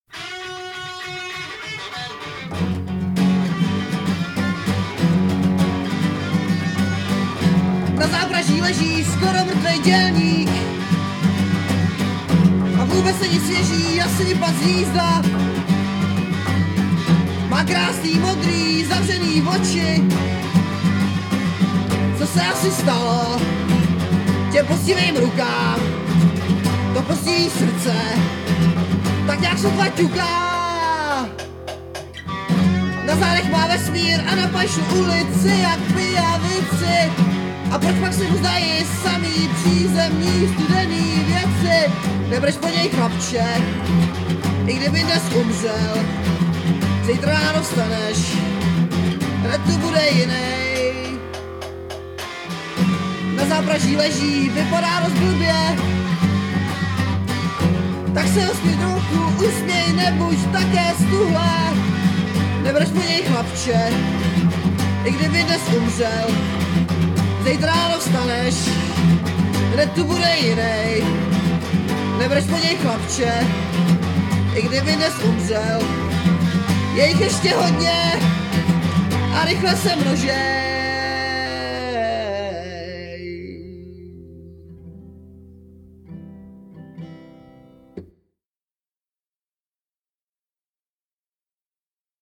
kbd, harm, dr
g, sitar, harm